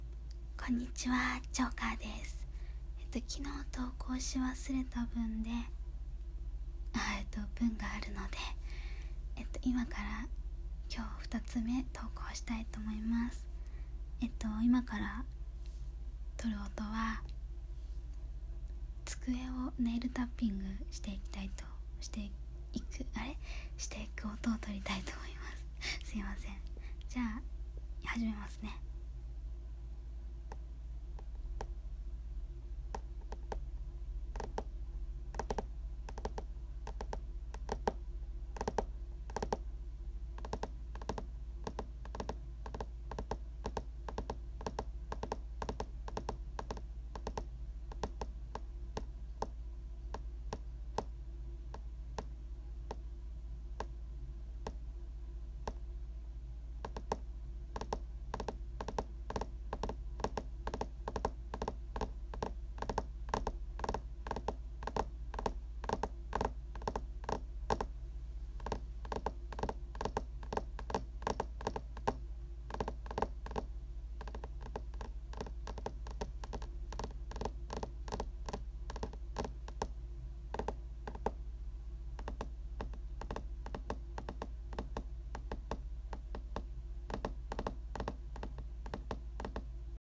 音フェチ★机をネイルタッピング